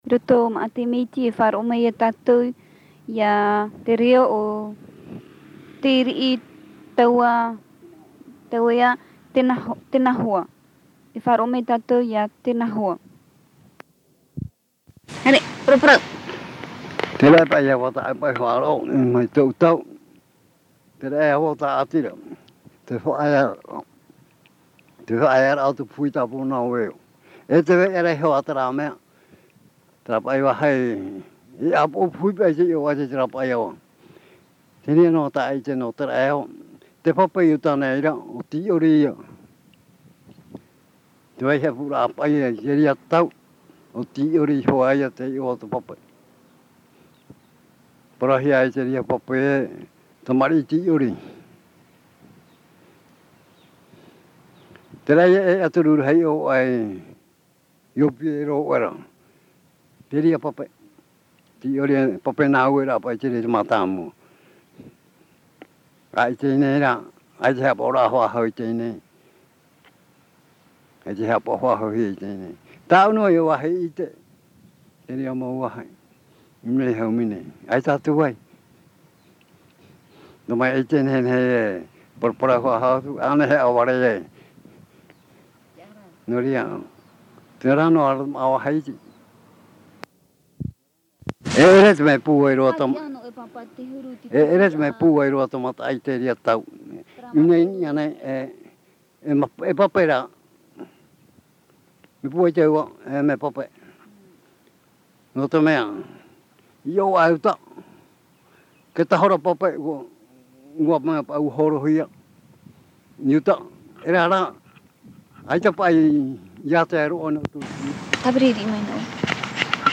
Descriptif de l'interview
Une femme prend ensuite la parole pour donner son avis.
Papa mātāmua / Support original : Cassette audio